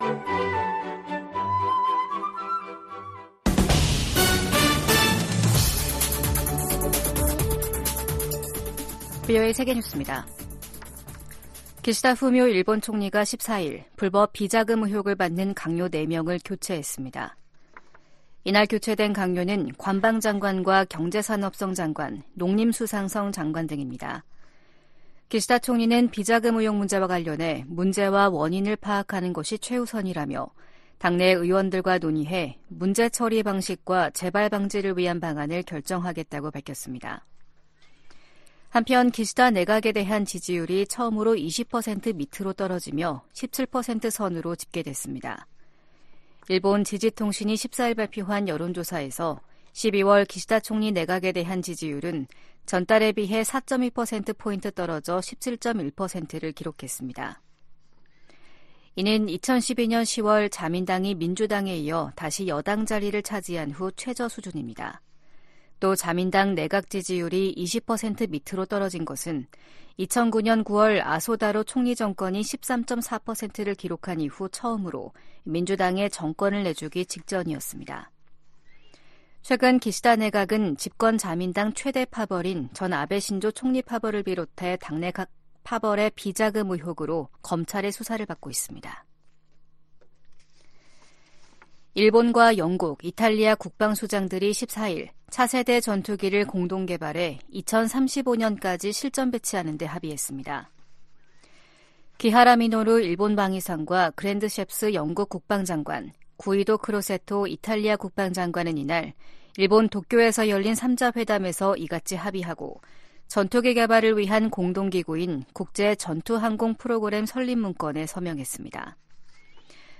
VOA 한국어 아침 뉴스 프로그램 '워싱턴 뉴스 광장' 2023년 12월 15일 방송입니다. 미국 정부가 북한 노동자들의 러시아 파견 정황에 대해 북러 협력 문제의 심각성을 지적했습니다. 미국 법무부 고위 당국자가 북한을 미국 안보와 경제적 이익에 대한 위협 가운데 하나로 지목했습니다. 네덜란드가 윤석열 한국 대통령의 국빈 방문을 맞아 북한의 미사일 발사를 비판하며 핵실험 자제를 촉구했습니다.